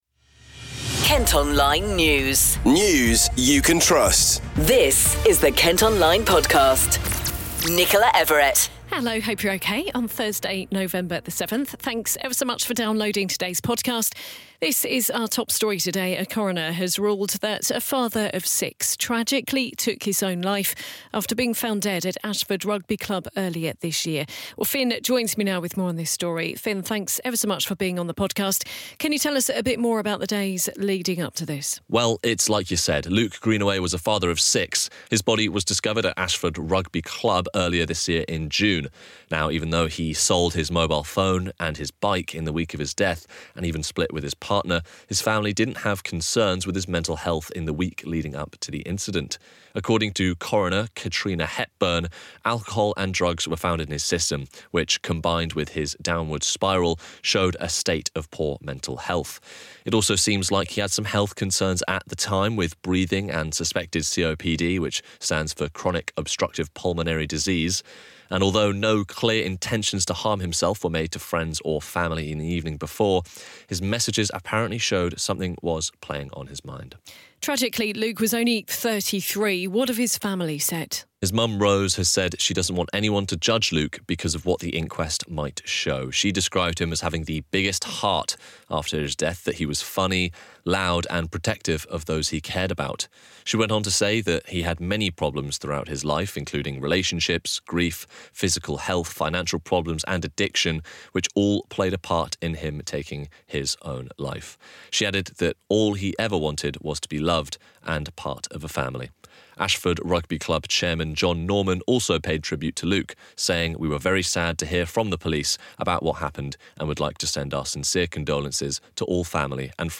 We’ve been asking people in Rochester what they think.